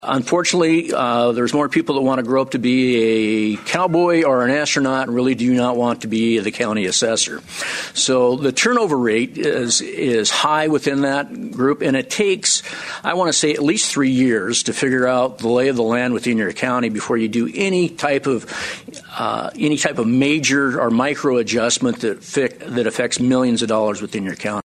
Task Force Chairman Representative Kirk Chaffee of Whitewood says there is a lack of experience among county assessors.